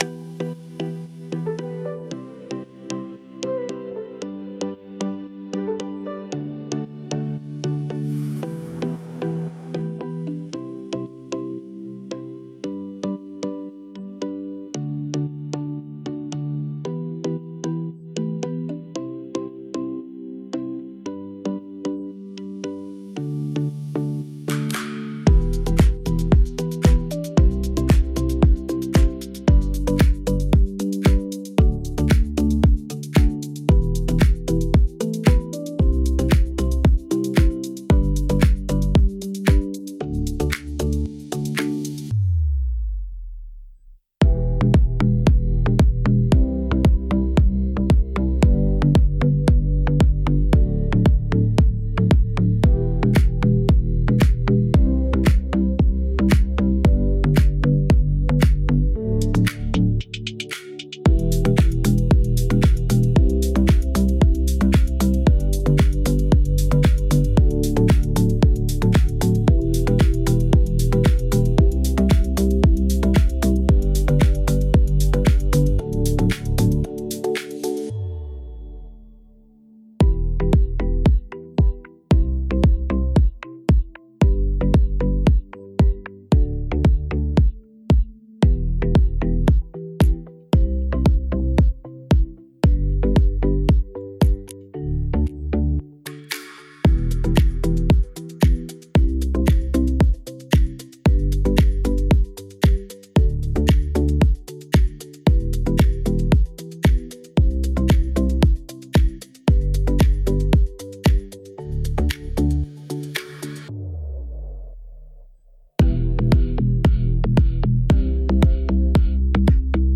Tropical House · 114 BPM · Eng